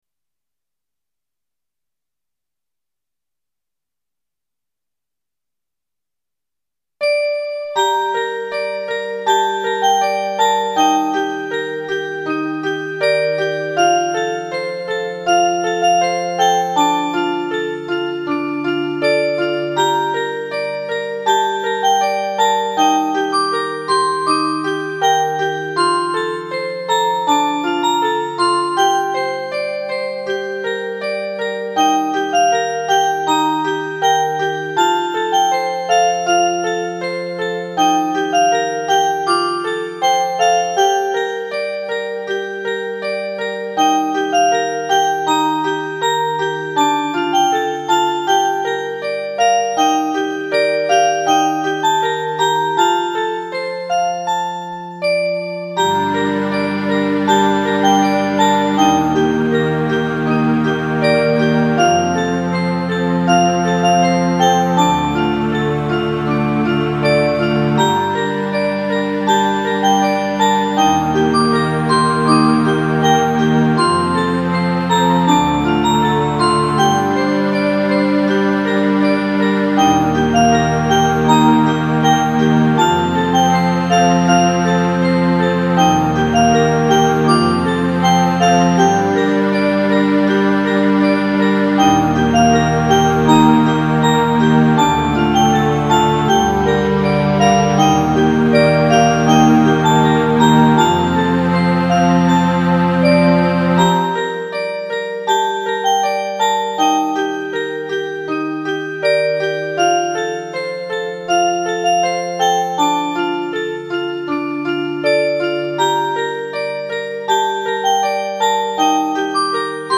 リバーブかけすぎたぁ〜汚かったらごめんなさい。
後半のスロー弦楽器がいい感じです。
オルゴールだけどもやもや、そんな感じ（意味不
ＶＳＣ音源があうのはこれぐらいか......